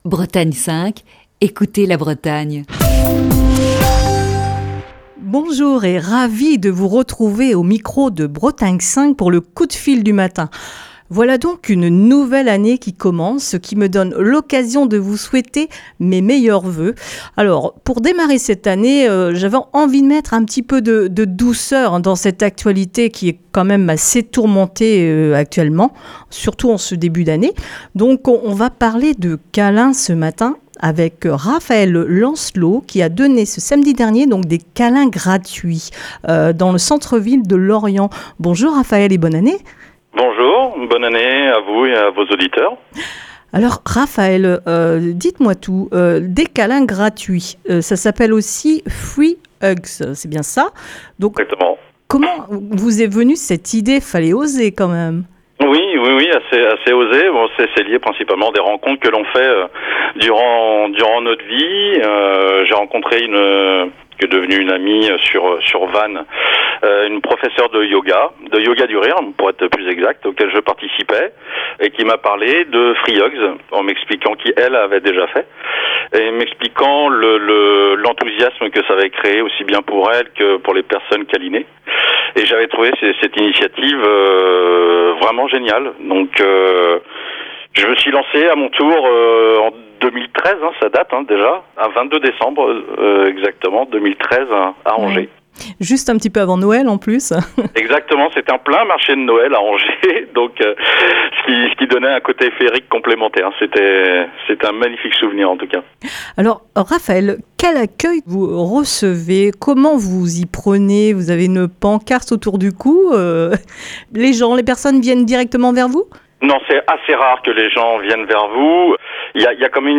Coup de fil du Matin. Pour ce premier coup de fil de l'année